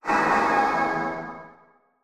Add SFX